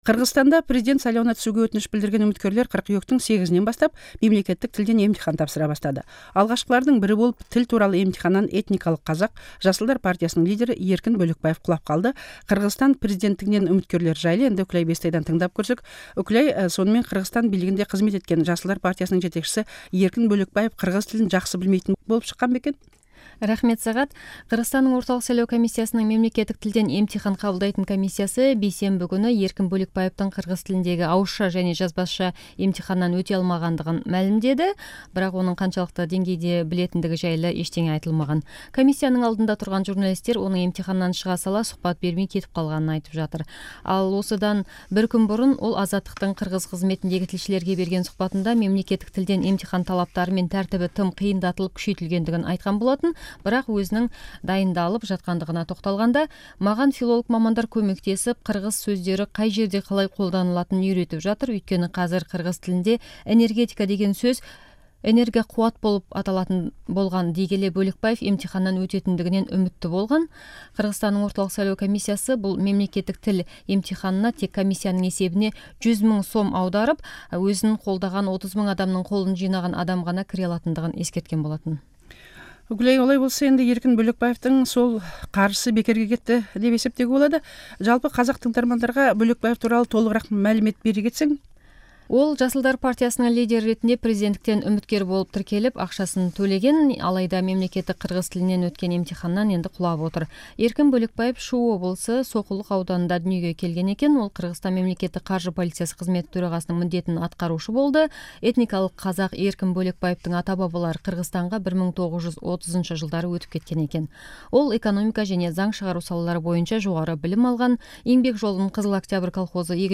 Еркін Бөлекбаев жайлы радиохабарды тыңдаңыз